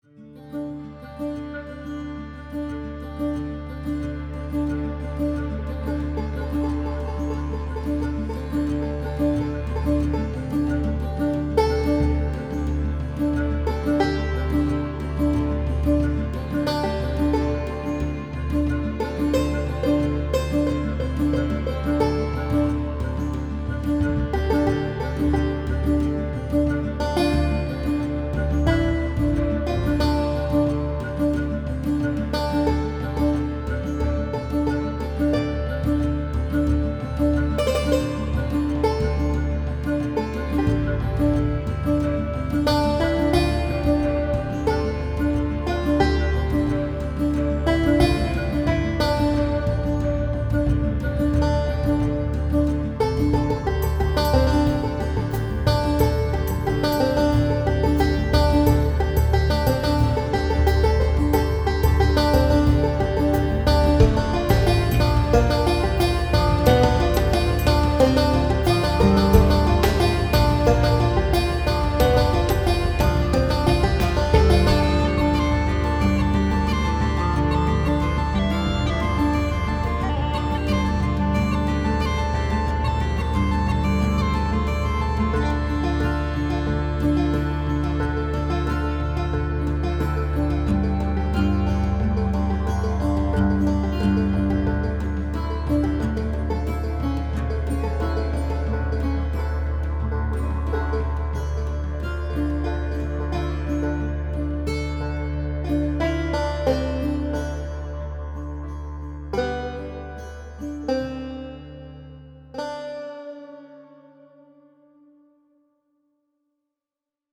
This is just magical, nice, nice quality of sound.
It's more ambient, but very good. Nice ethnic things here! 15,5/20
Très bonne ambiance, la chevauchée est bien retranscrite.